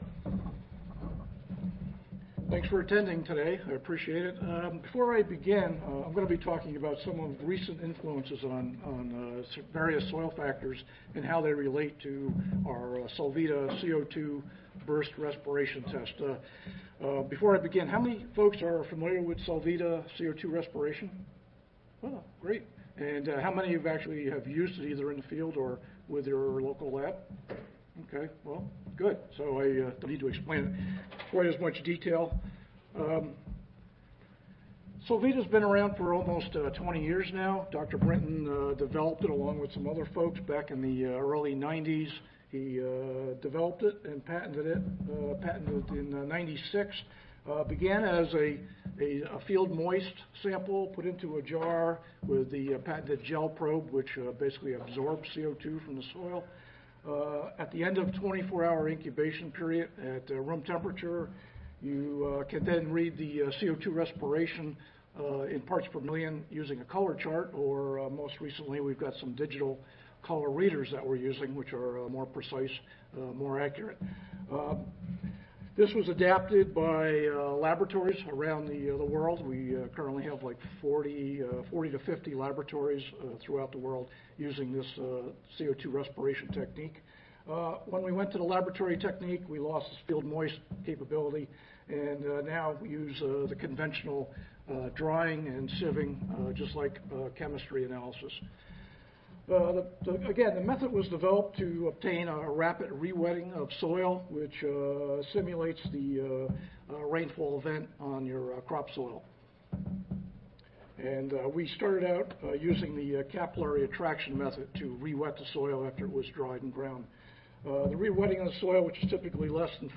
Session: Symposium--New Technologies for Soil Scientists in the 21st Century (ASA, CSSA and SSSA International Annual Meetings (2015))
Solvita Respiration Test Systems Audio File Recorded Presentation